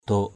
/d̪o:k/ (d.) cờ = drapeau. flag. hala dok hl% _dK lá cờ. gram dok g’ _dK quốc kỳ. dok duan _dK d&N cờ xí = drapeaux.